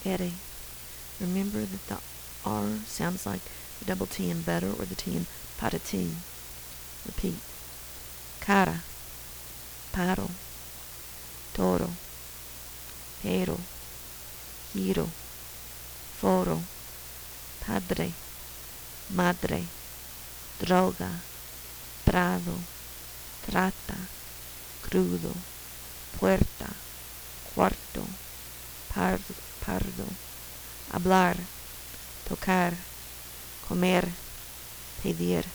Sounds that are pretty different from English
the t in “pot o’ tea”